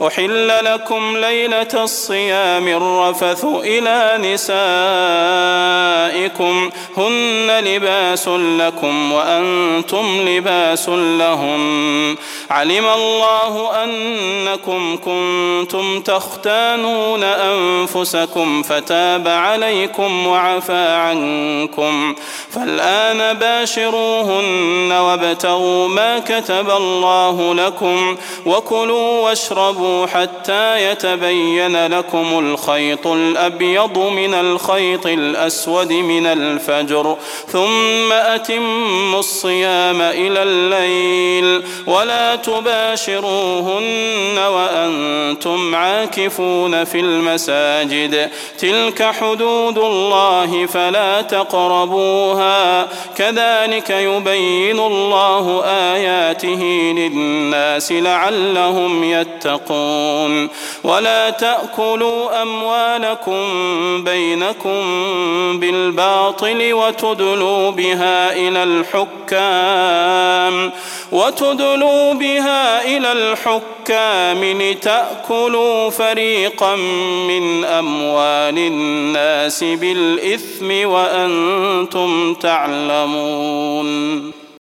جديد - البقرة 187-188 البدير تهجد 1423